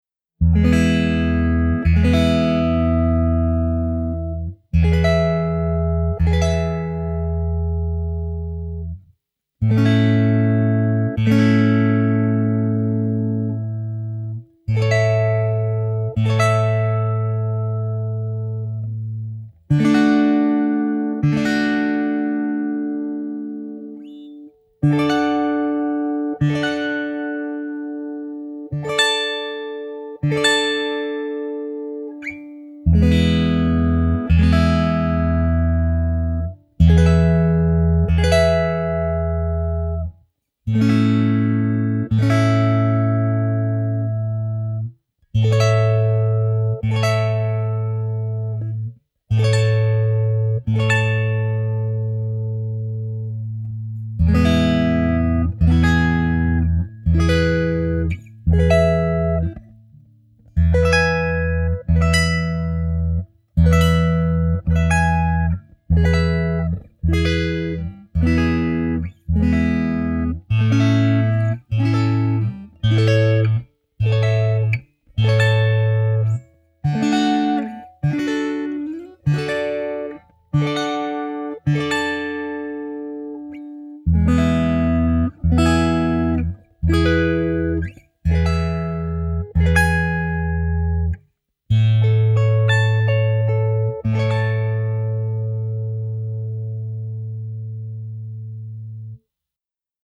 Звук Акустики